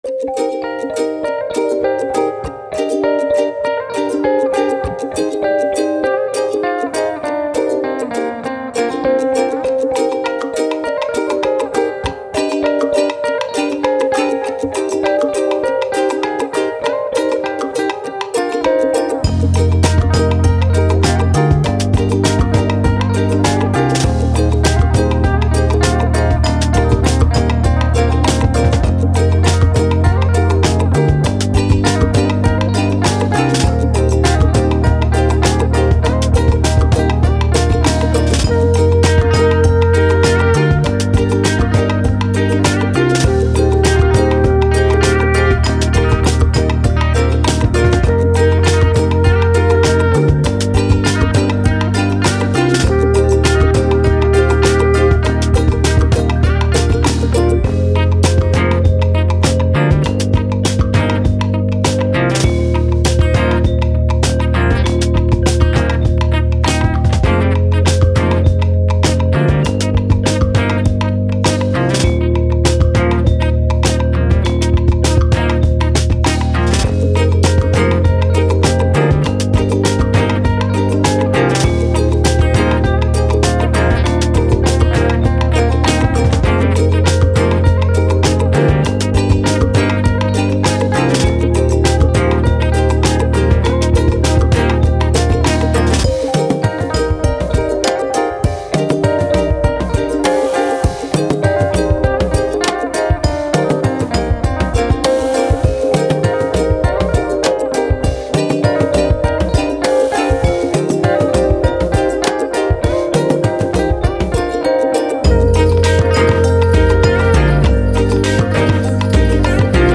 Pop, Playful, Ukulele